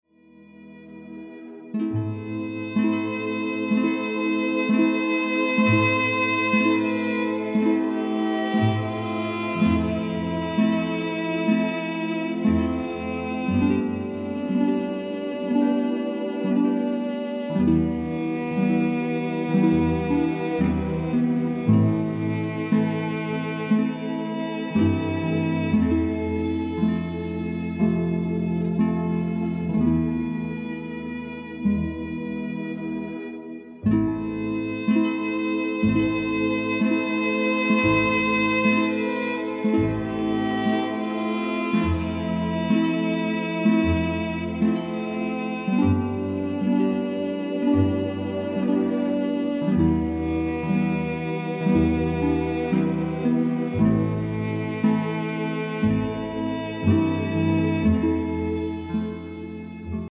Entspannungsmusik
heilende Klänge
Instrumentalmusik
Meditationsmusik